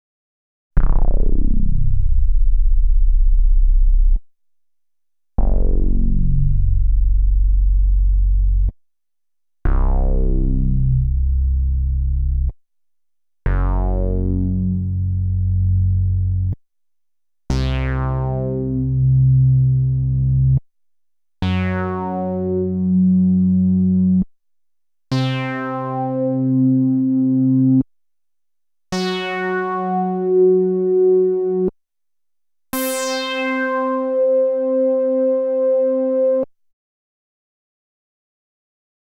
02_Bass_2.wav